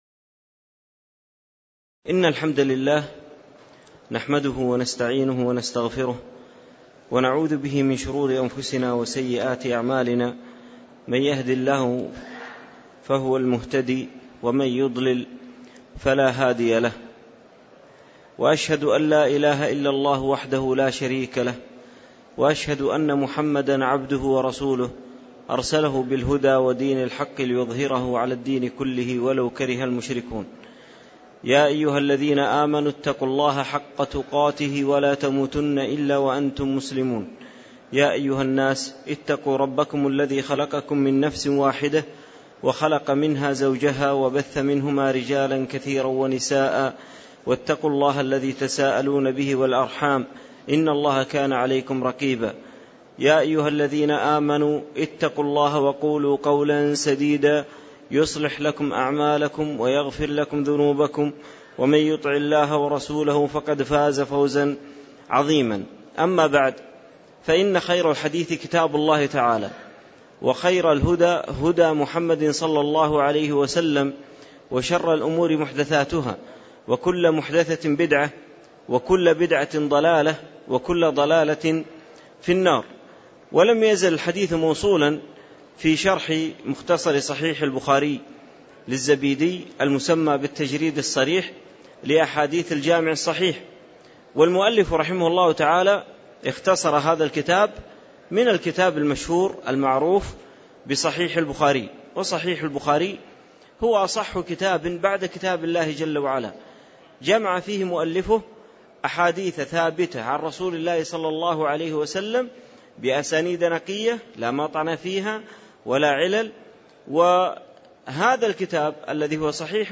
تاريخ النشر ٢٩ محرم ١٤٣٧ هـ المكان: المسجد النبوي الشيخ